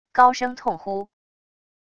高声痛呼wav音频